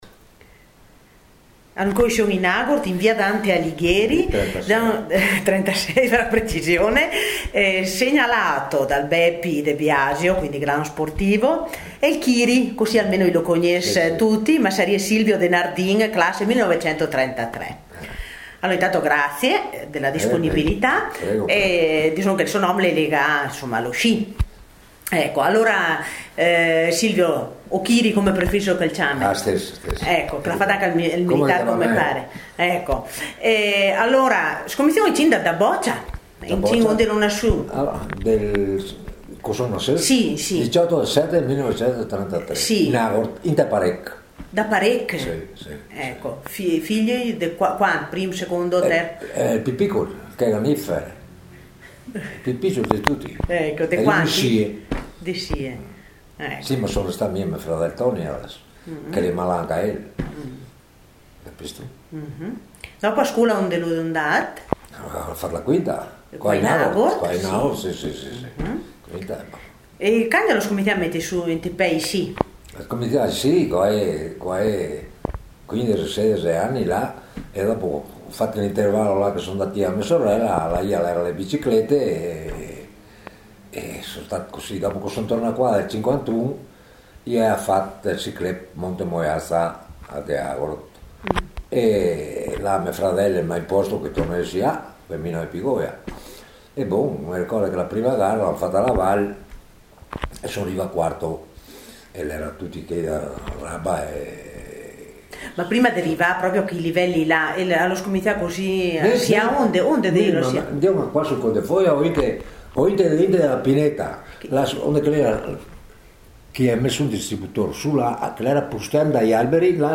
Oggi in suo ricordo riproponiamo l’intervista di allora.